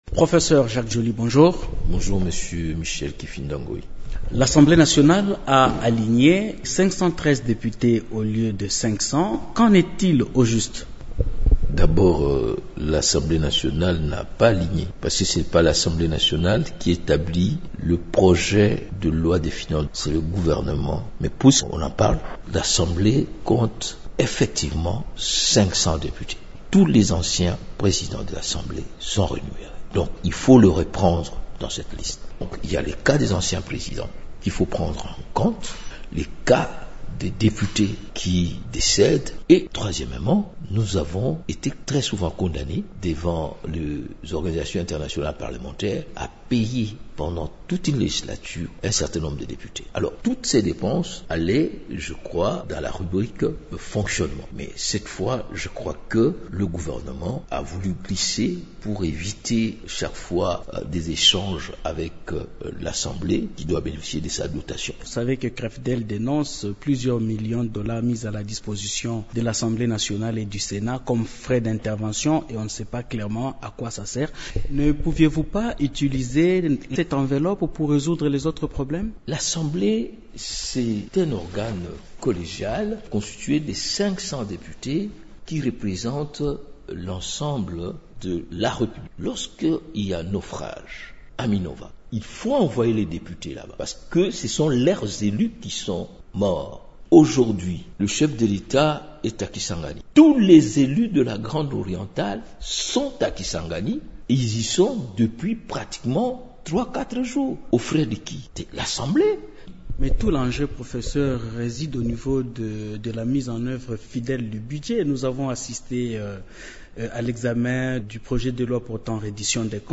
C’est plutôt le Gouvernement qui élabore ce projet, précise-t-il, dans une interview accordée jeudi 24 octobre à Radio Okapi.